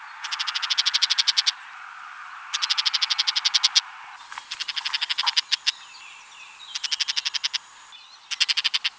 DeWiki > Rotkopfwürger
call2.wav